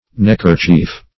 neckerchief - definition of neckerchief - synonyms, pronunciation, spelling from Free Dictionary
Search Result for " neckerchief" : Wordnet 3.0 NOUN (1) 1. a kerchief worn around the neck ; The Collaborative International Dictionary of English v.0.48: Neckerchief \Neck"er*chief\, n. [For neck kerchief.]